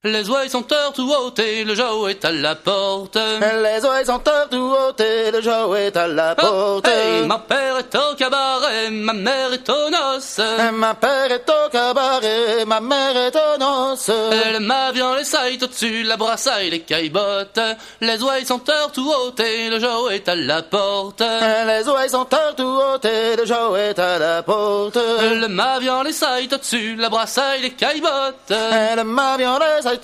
La Ronde avant-deux est une danse en ronde du Poitou.